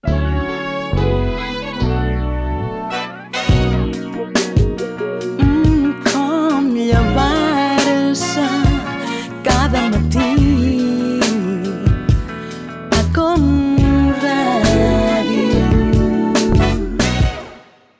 Jingle cantat del programa